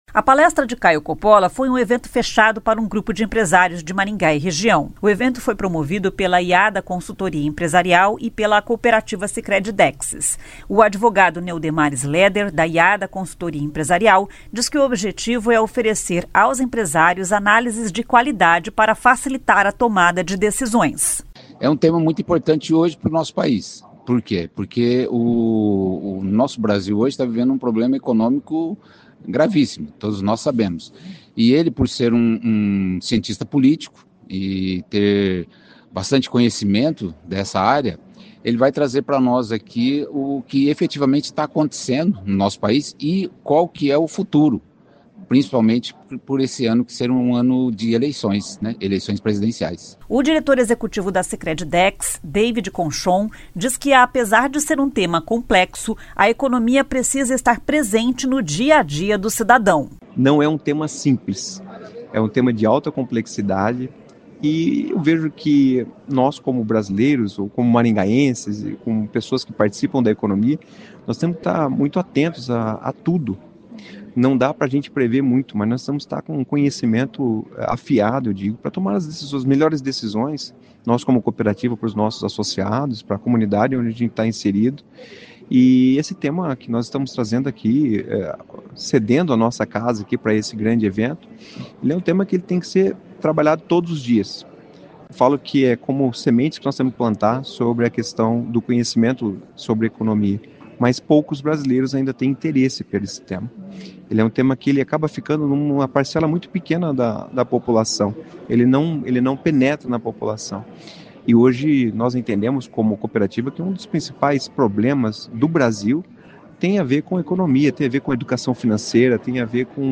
Em palestra, Caio Coppolla fala sobre cenário político e econômico em 2026
O comentarista político Caio Coppolla foi o convidado de um evento para empresários de Maringá nessa quarta-feira (4).